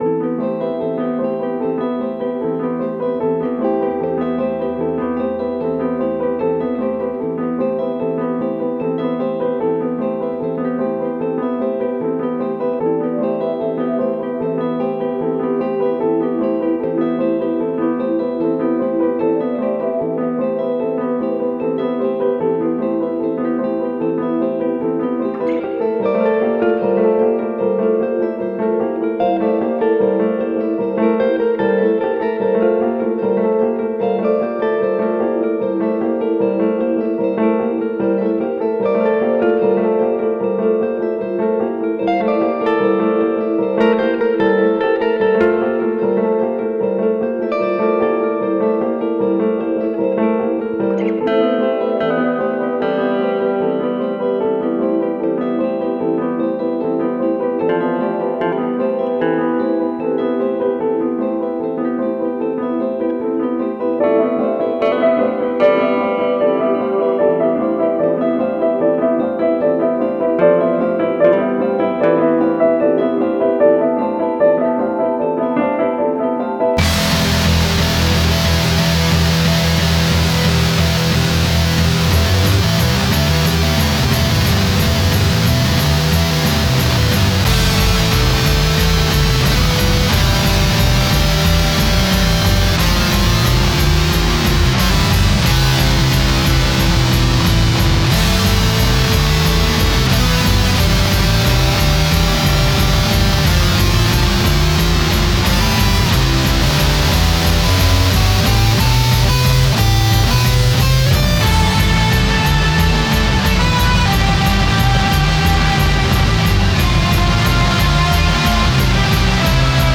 Home > Music > Rock > Dreamy > Running > Floating